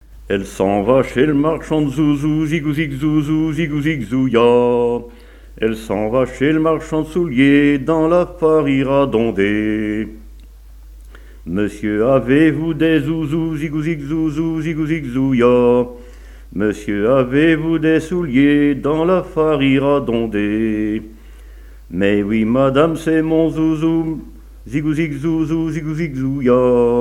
Chansons et témoignages sur le chanson et la musique
Pièce musicale inédite